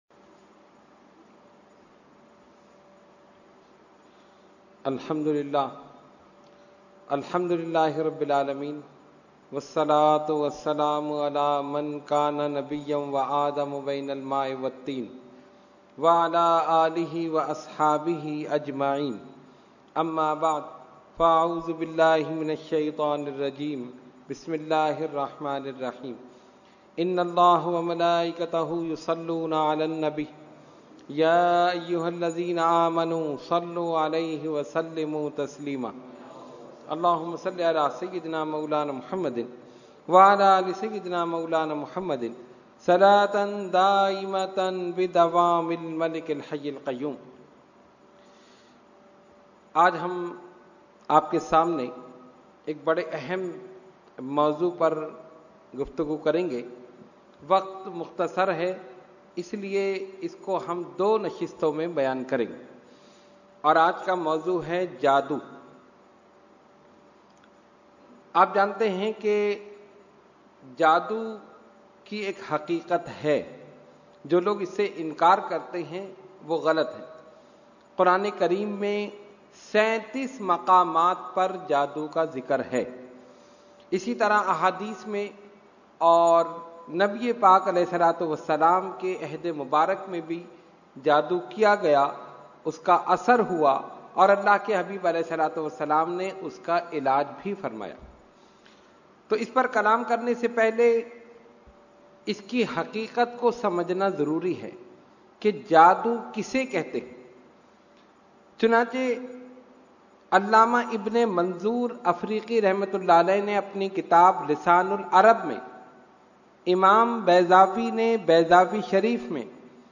Category : Speech | Language : UrduEvent : Weekly Tarbiyati Nashist